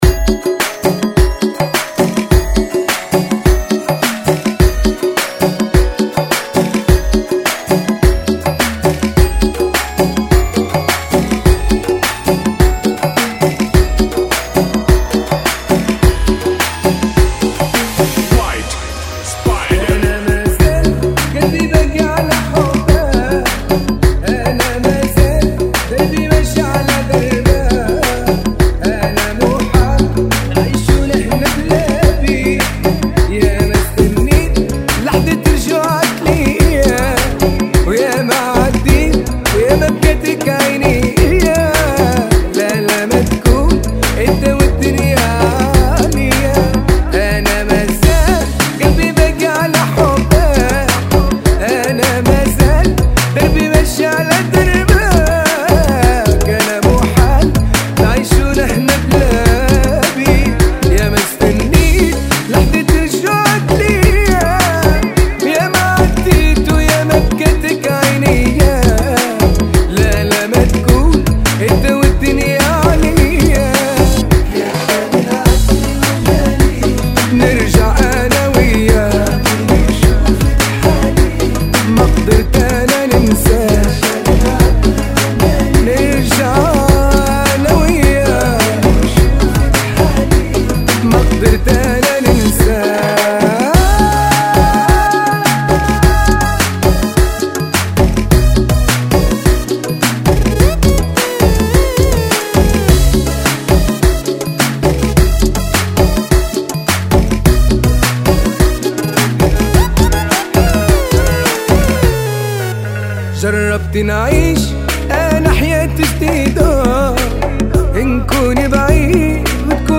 105 Bpm
Funky